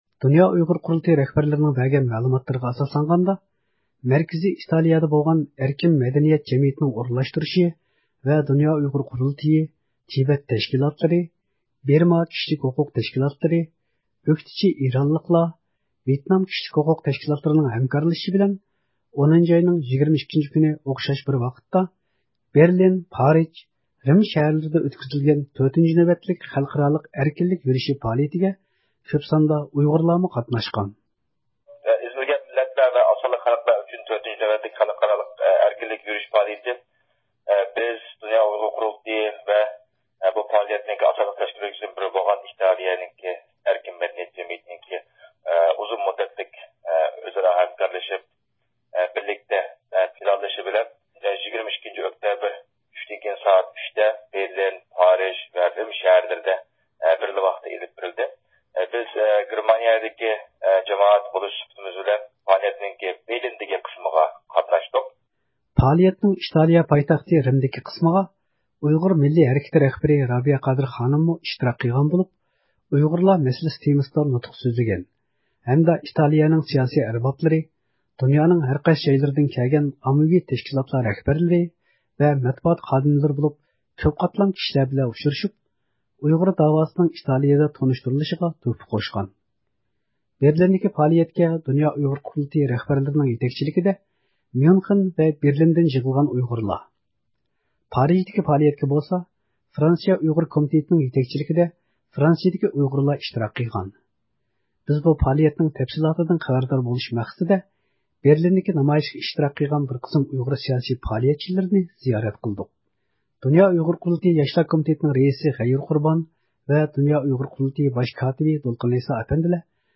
بىز بۇ پائالىيەتنىڭ تەپسىلاتىدىن خەۋەردار بولۇش مەقسىتىدە، بېرلىندىكى نامايىشقا ئىشتىراك قىلغان بىر قىسىم ئۇيغۇر سىياسىي پائالىيەتچىلىرىنى زىيارەت قىلدۇق.